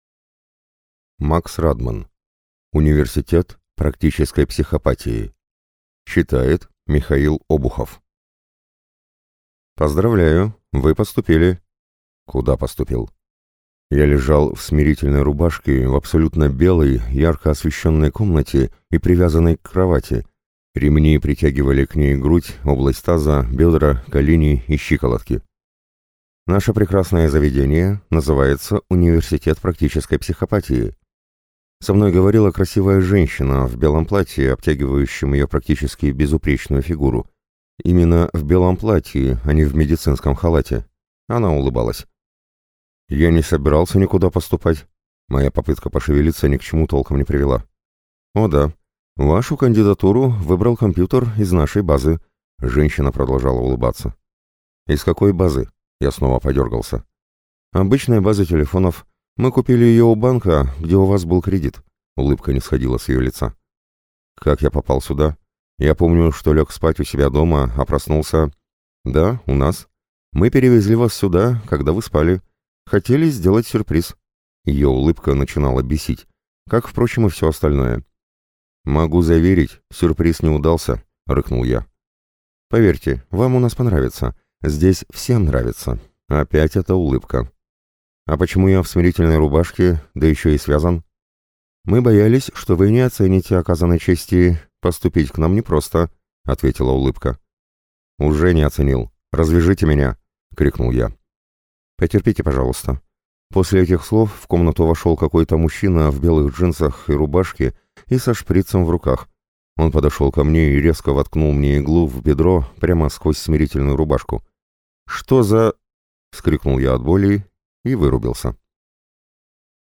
Аудиокнига Университет практической психопатии | Библиотека аудиокниг